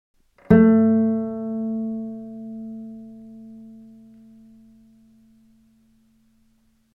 Un instrument de musique
violoncellela.mp3